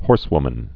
(hôrswmən)